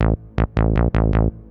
tb303-5.wav